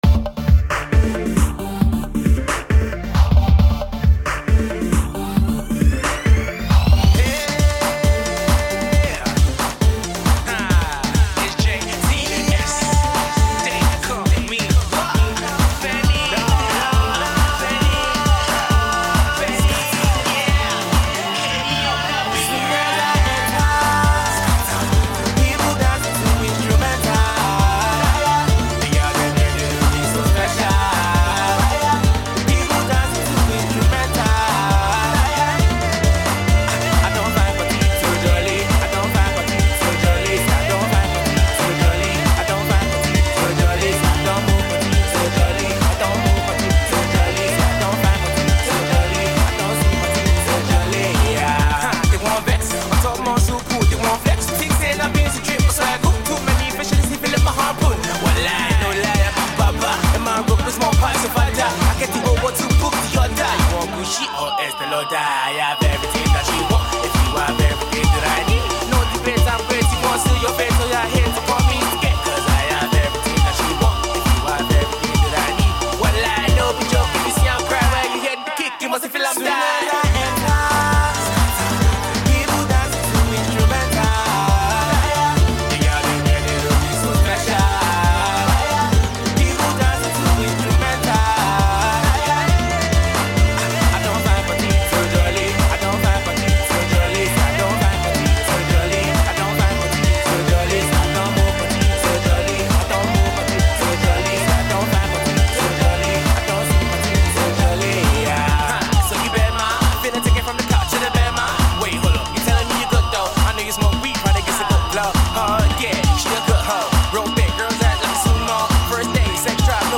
is laced with dope punchlines and nice flows